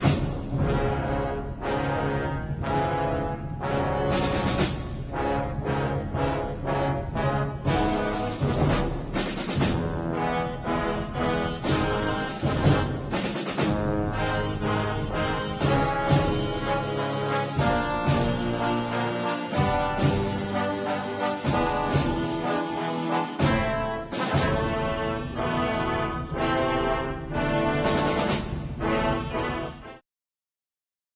Wind Band Version